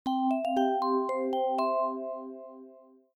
Level_Up.ogg